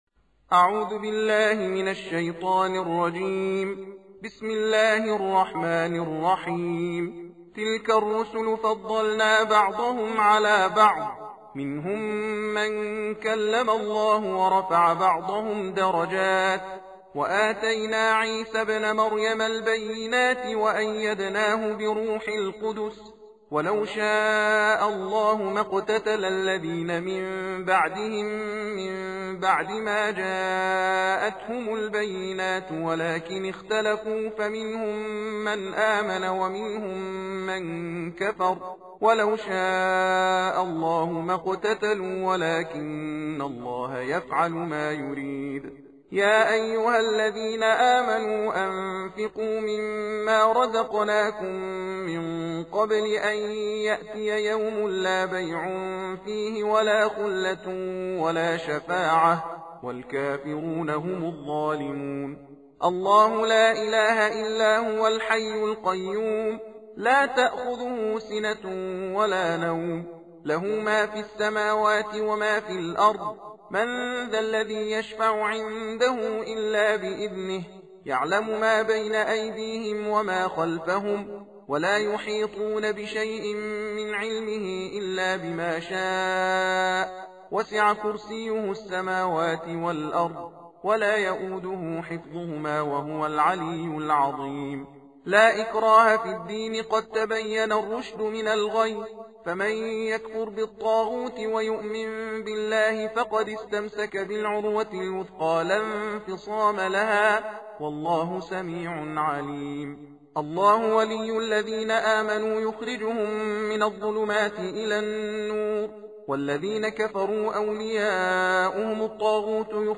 تحدیر جزء سوم قرآن کریم